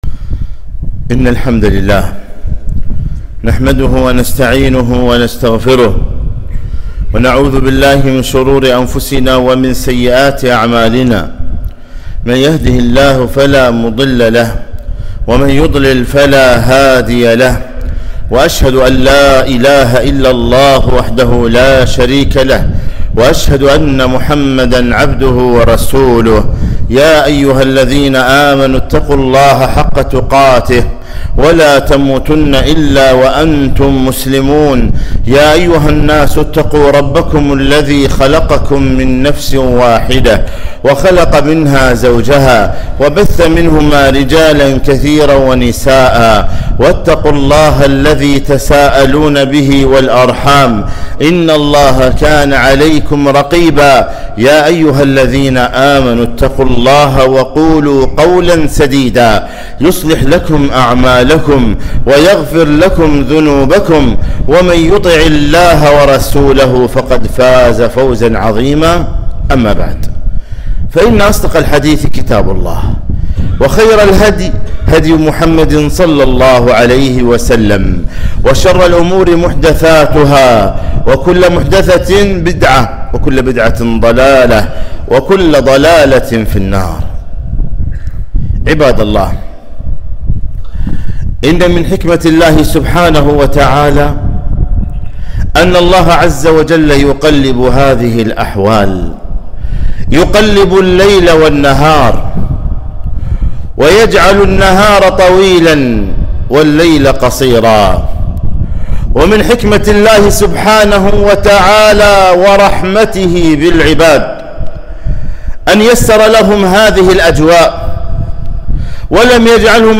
خطبة - مرحبا بالشتاء